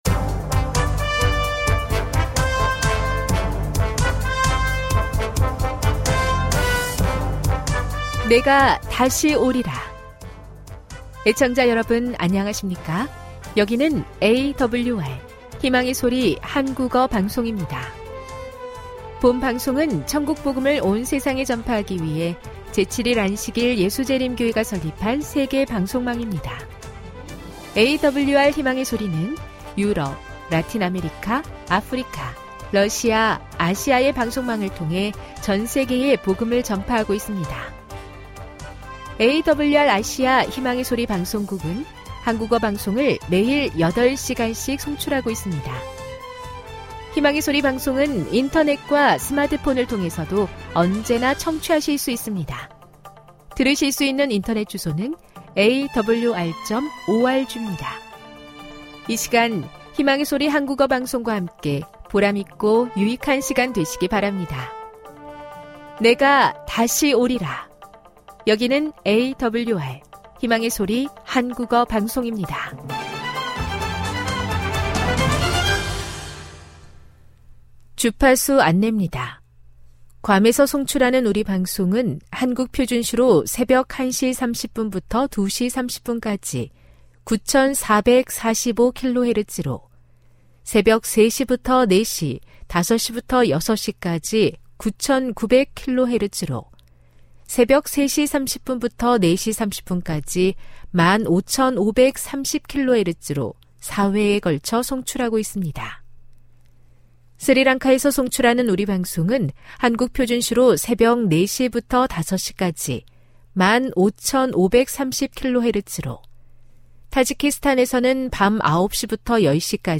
1 설교, 명상의 오솔길, 아름다운 세계 58:06